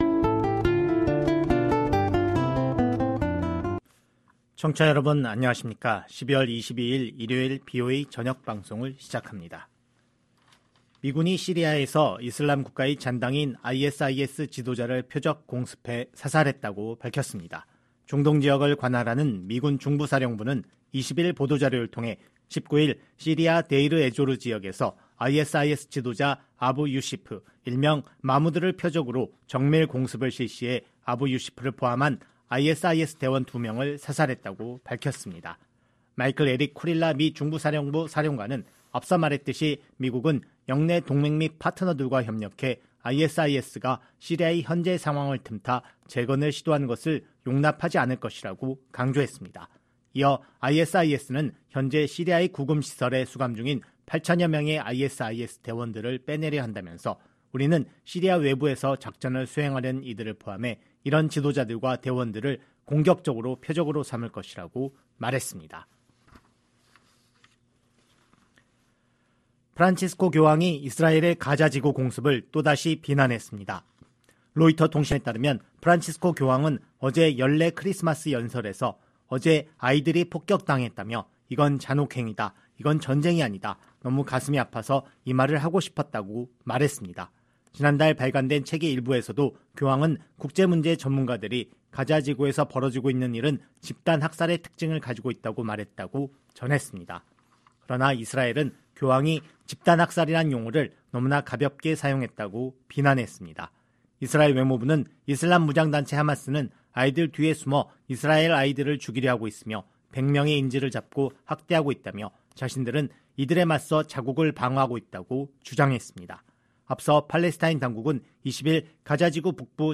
VOA 한국어 방송의 일요일 오후 프로그램 3부입니다. 한반도 시간 오후 10:00 부터 11:00 까지 방송됩니다.